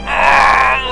Epic noise - Aaah
Category: Sound FX   Right: Personal